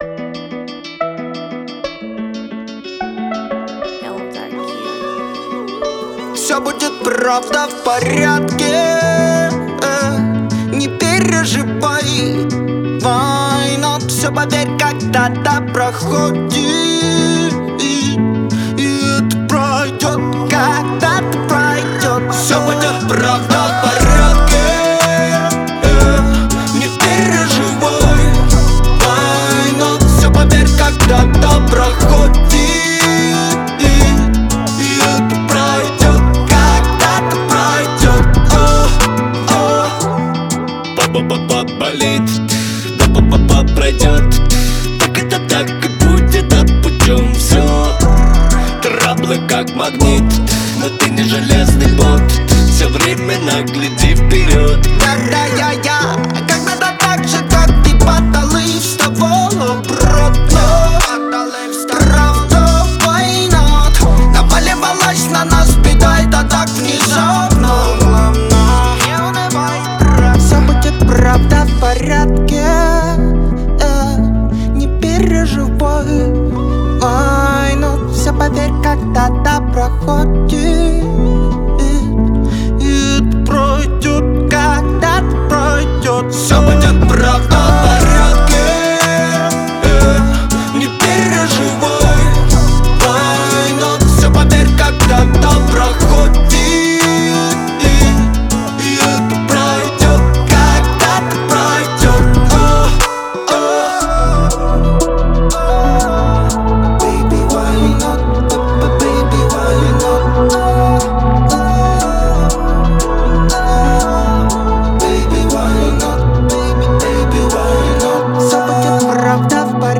это энергичная композиция в жанре поп-рэп